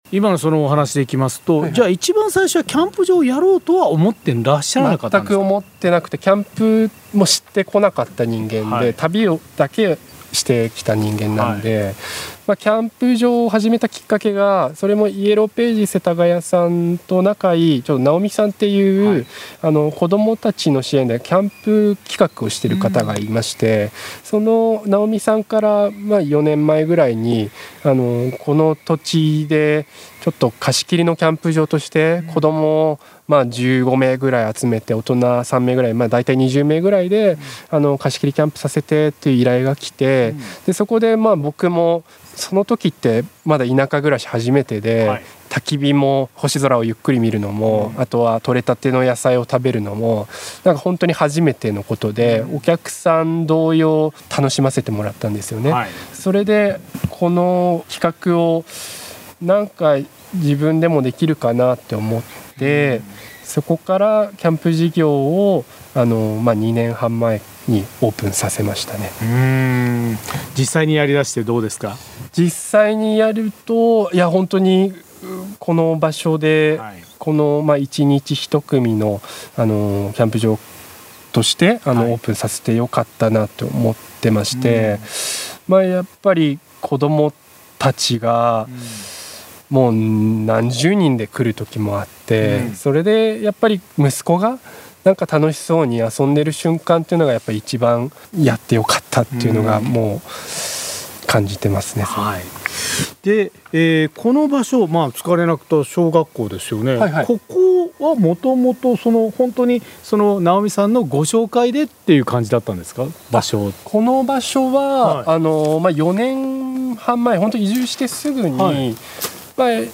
毎週土曜午前11時から生放送。